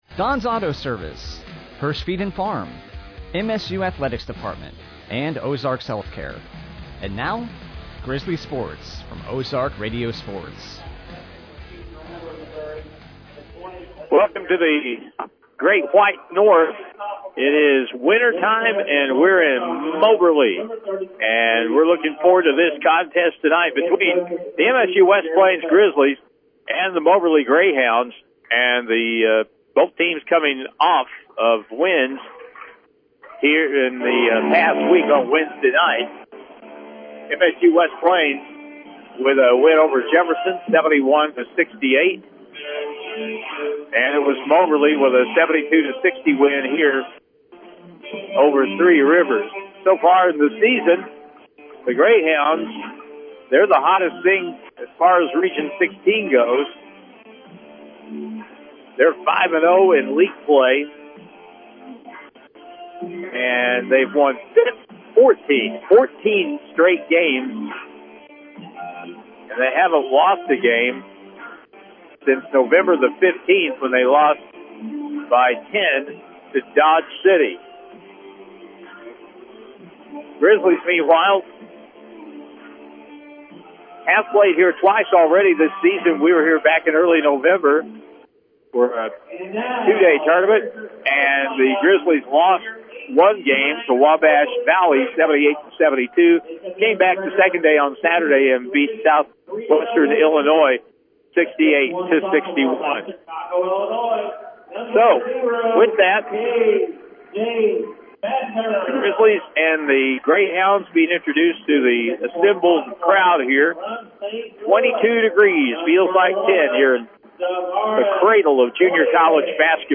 Game Audio